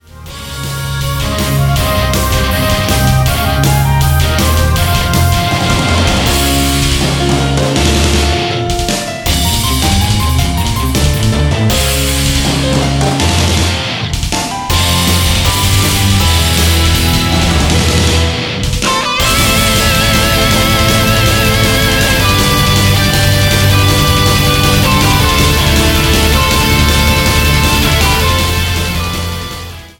Arrange Album
今作は、重苦しさと疾走感を同居させたアレンジ傾向となっている。
軽やかに流れる前作と比較し、ずっしりと重い重量感と疾走感が同居している。